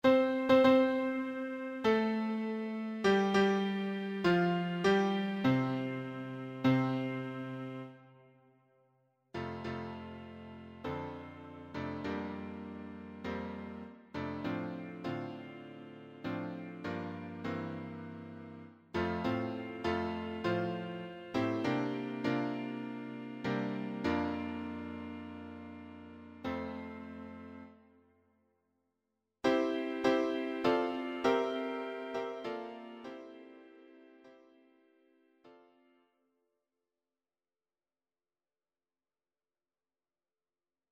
choir SATB
Electronically Generated
Sibelius file